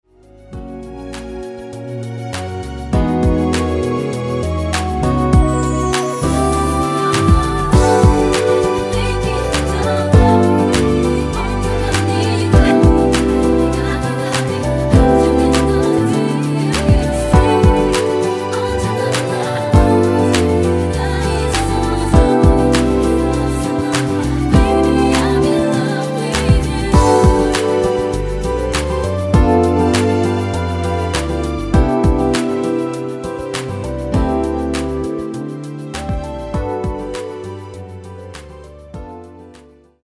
코러스 MR 입니다.
원키 코러스 포함된 MR 입니다.(미리듣기 나오는 부분 코러스 입니다.)
앞부분30초, 뒷부분30초씩 편집해서 올려 드리고 있습니다.